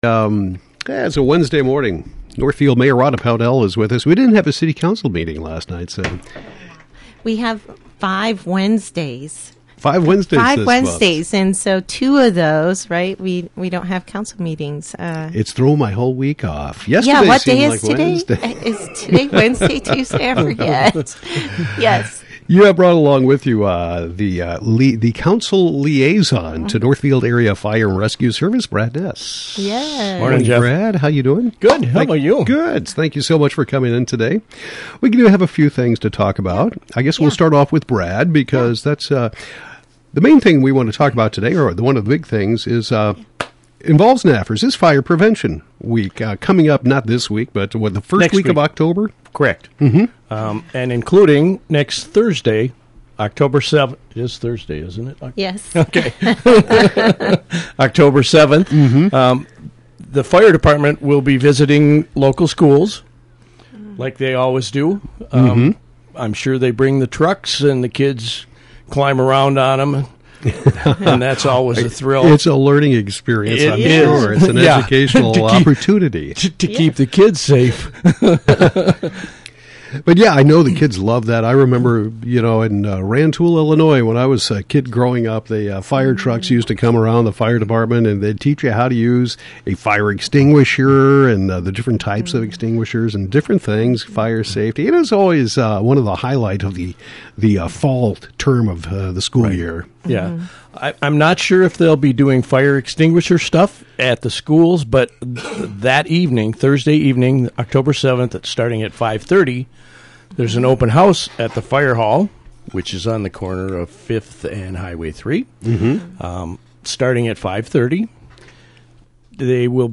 Northfield Mayor Rhonda Pownell and City Councilor Brad Ness discuss fire prevention week. There will be an open house at the fire hall on October 7 beginning at 5:30 p.m. Mayor Pownell announces the Northfield Emerging Leaders Program.